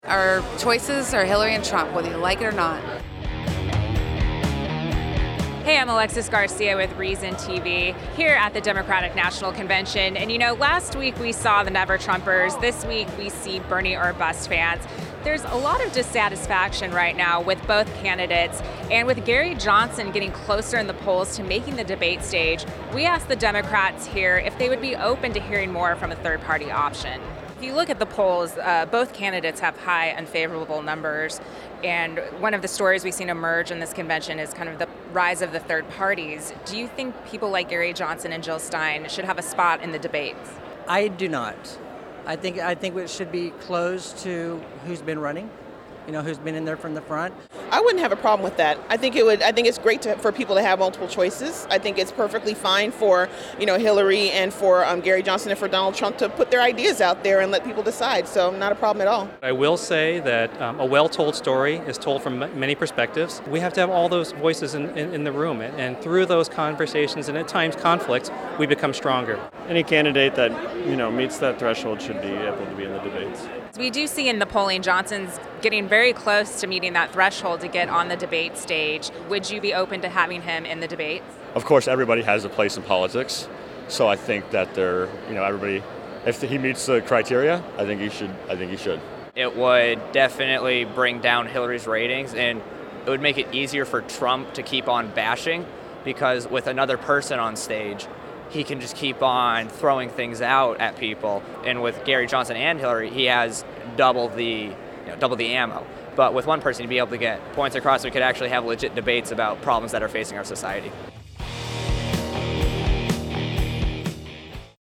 With this in mind, Reason TV asked delegates at the Democratic National Convention in Philadelphia whether or not they believe third-party candidates like Libertarian Gary Johnson or the Green Party's Jill Stein should be in the presidential debates.